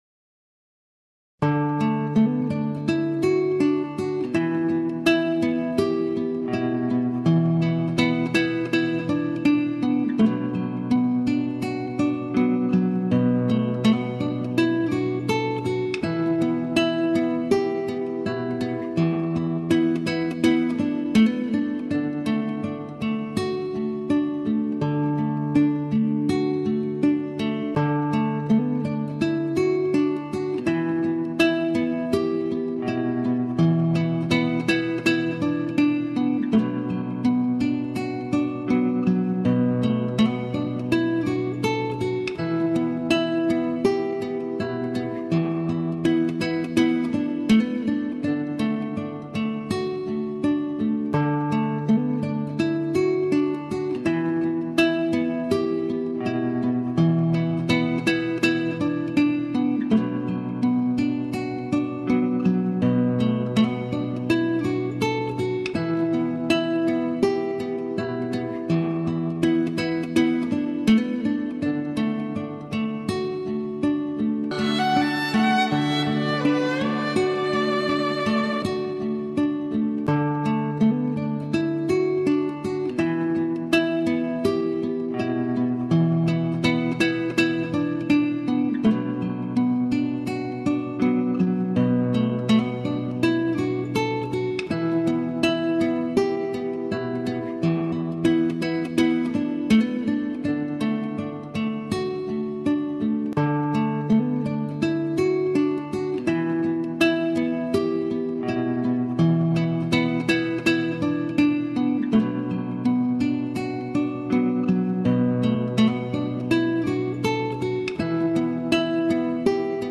无 调式 : D 曲类